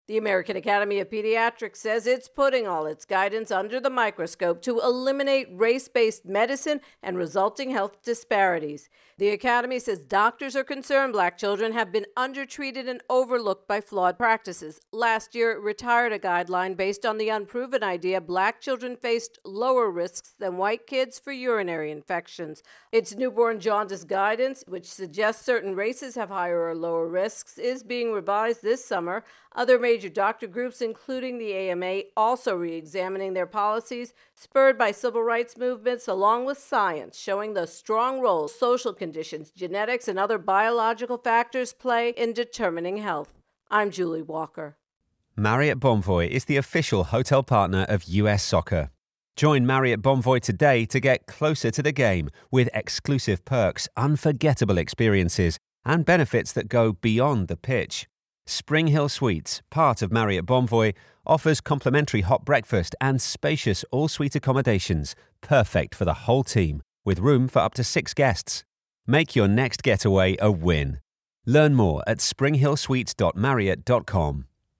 Pediatricians Race intro and voicer